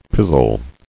[piz·zle]